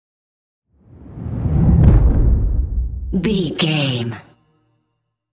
Cinematic whoosh to hit deep fast
Sound Effects
Fast
dark
intense
tension
woosh to hit